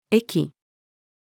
駅-female.mp3